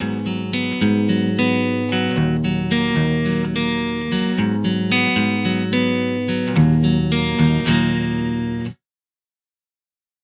Guitar_Lost_110bpm_G#m
Guitar_Lost_110bpm_Gm.wav